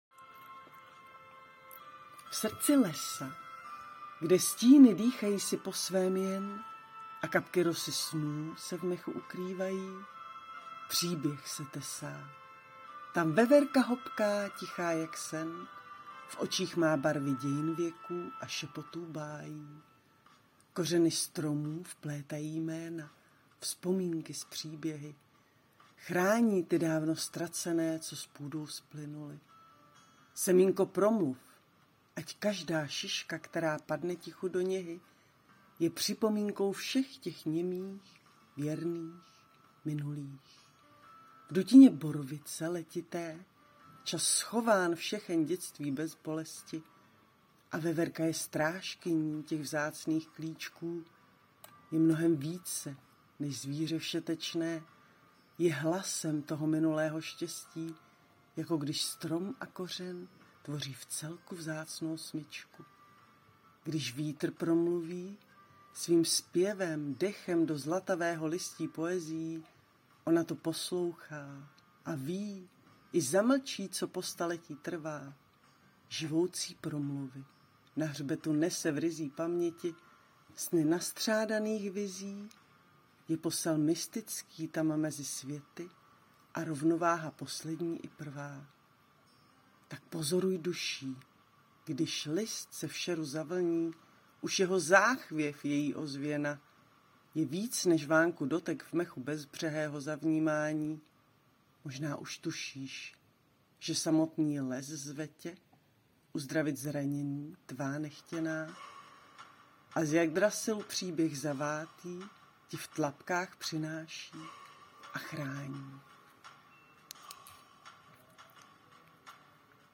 Rád jsem slyšel Tvůj vlídný hlas!